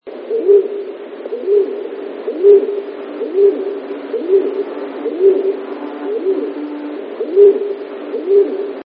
Siniak - Columba oenas
głosy